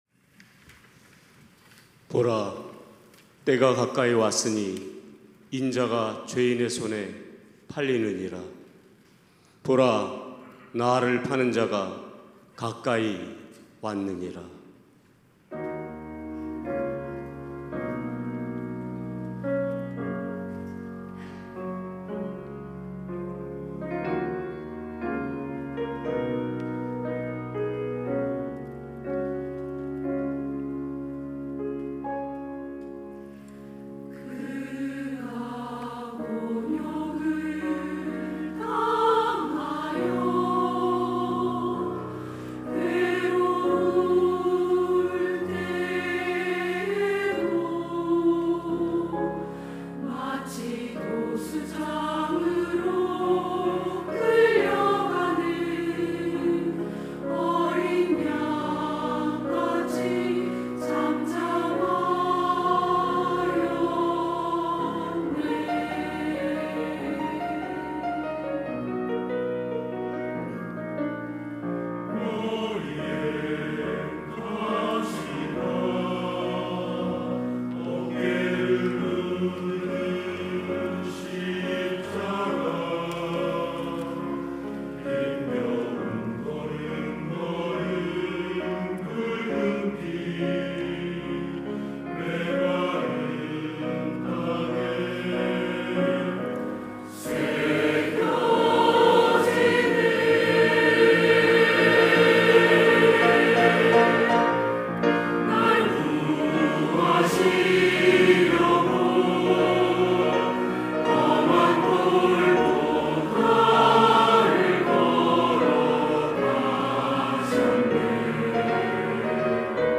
시온(주일1부) - 날 구하시려고
찬양대